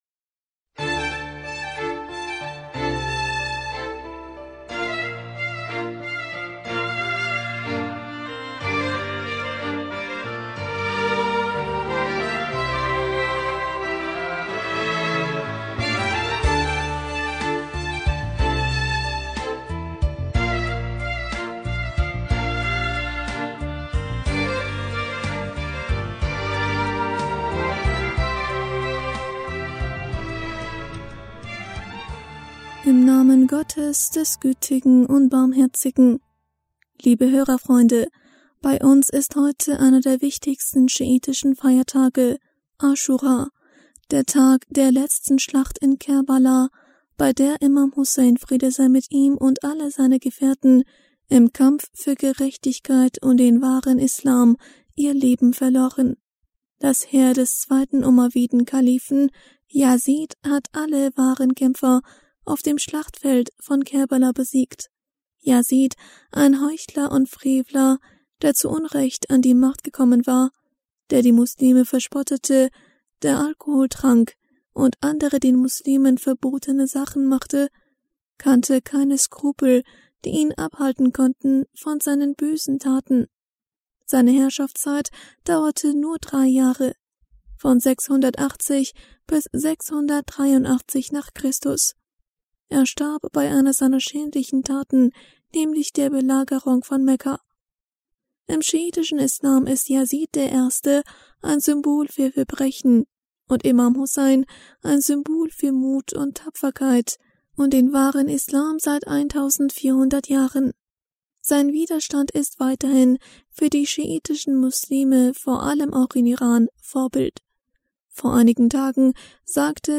Hörerpostsendung am 30. August 2020 Bismillaher rahmaner rahim - Liebe Hörerfreunde, bei uns ist heute einer der wichtigsten schiitischen Feiertage – Asch...